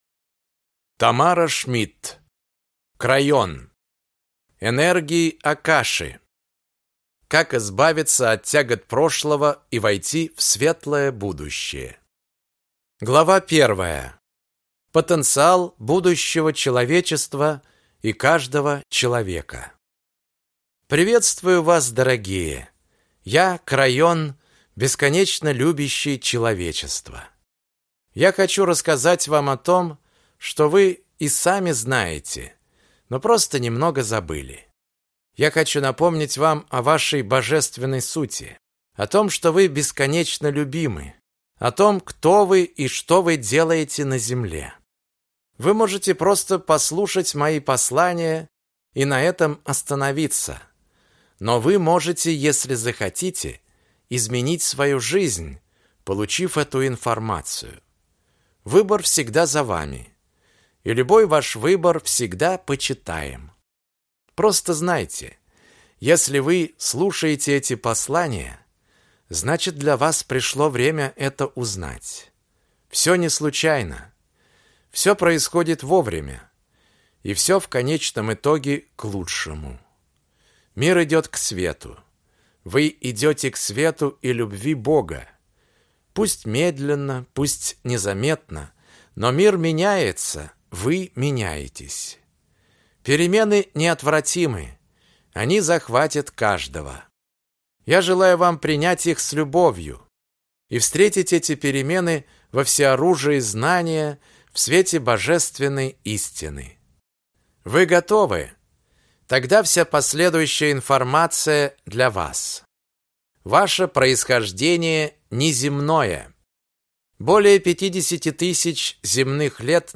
Аудиокнига Крайон. Энергии Акаши. Как избавиться от тягот прошлого и войти в светлое будущее | Библиотека аудиокниг